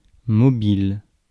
Mobile (/mˈbl/ moh-BEEL, French: [mɔbil]